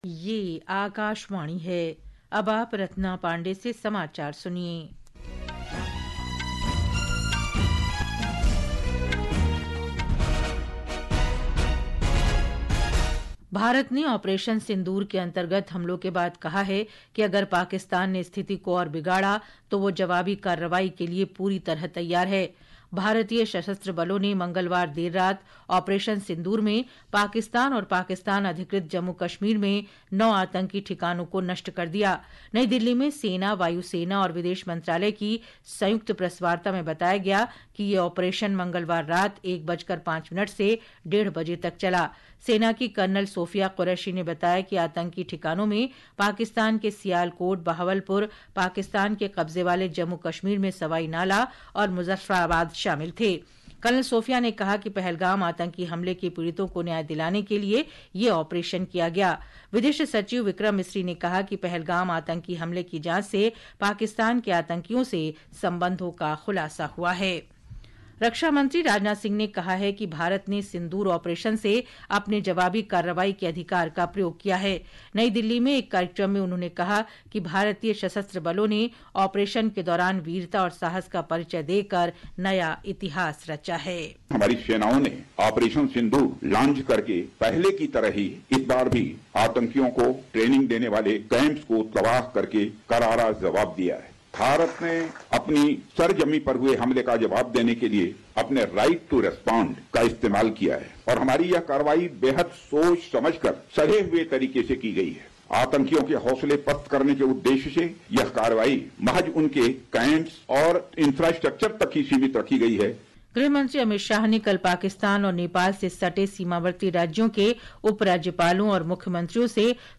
प्रति घंटा समाचार | Hindi